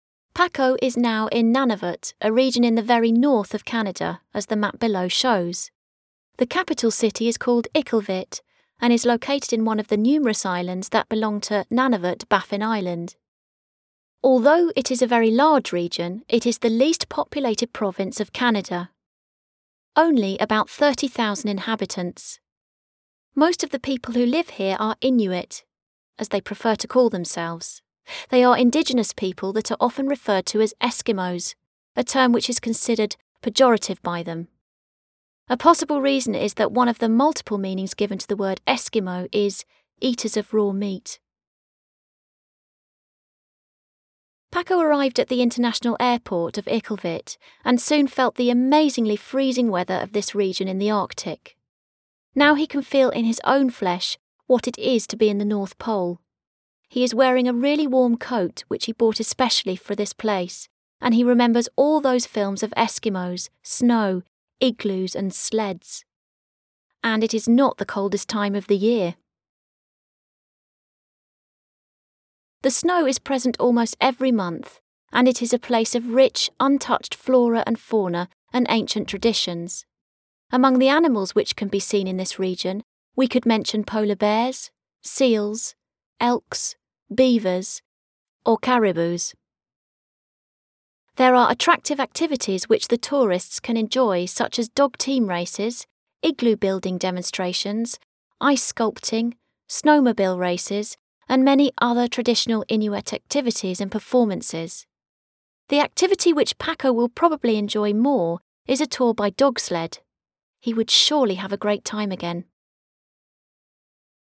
Audio Reading: Paco visits the north of Canada Paco is now in Nunavut, a region in the very north of Canada, as the map below shows.